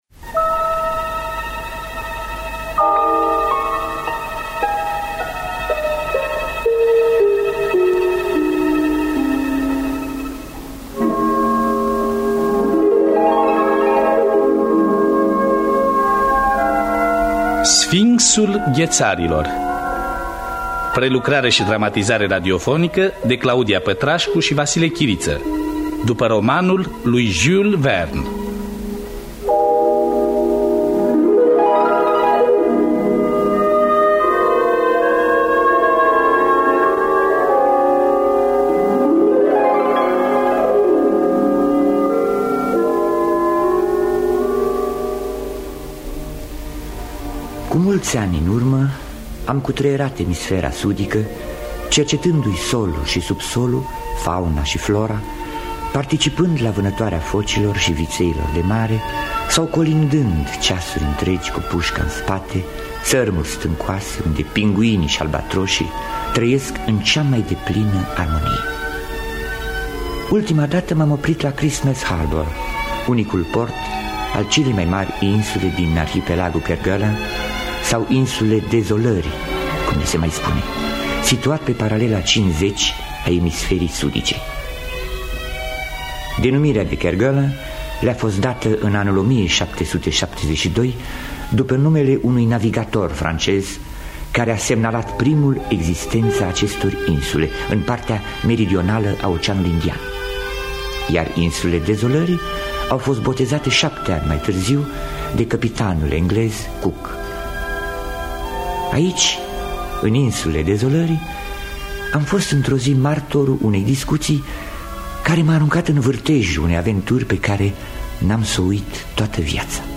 Dramatizarea şi adaptarea radiofonică